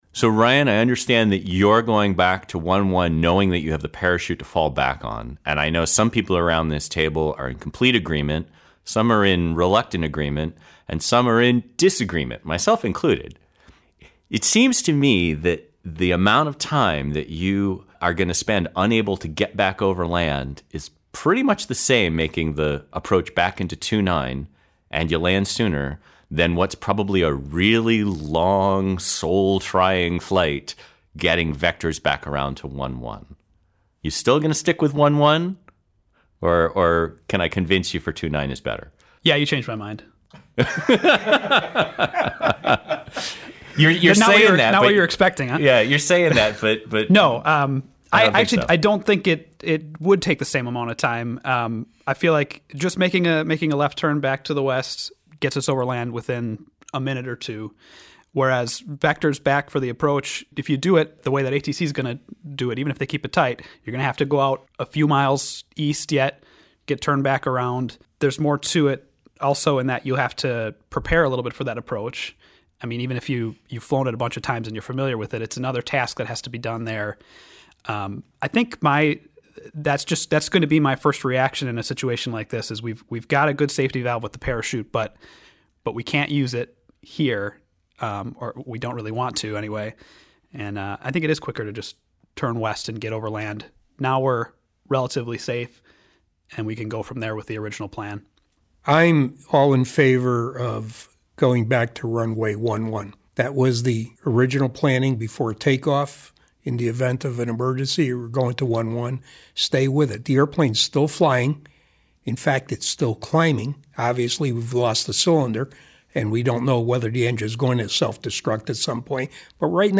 Listen in as our team of instructors discuss and debate the details of this scenario.
Rapid_return_in_portland_roundtable.mp3